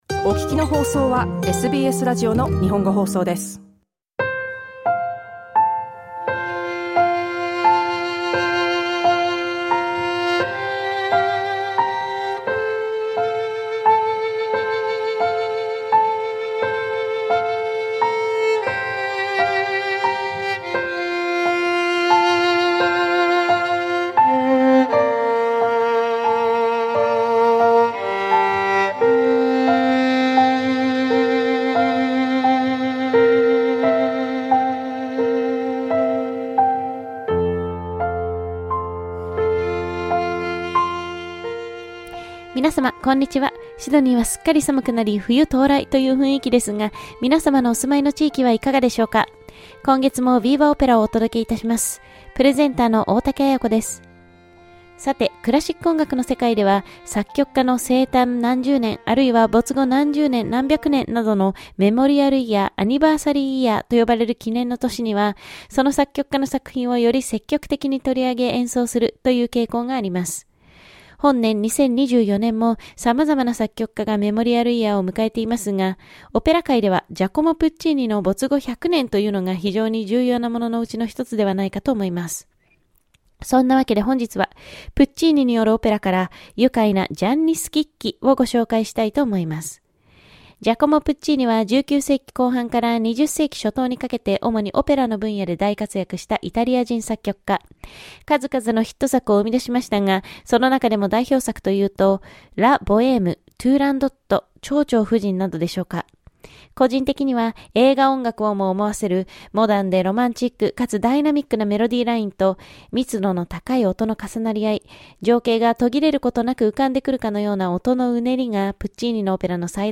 ラジオ番組で解説に続いて紹介するのは、バロセロナ出身のソプラノ歌手モンセラート・カバリェによる歌唱です。